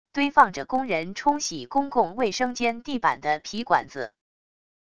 堆放着工人冲洗公共卫生间地板的皮管子wav音频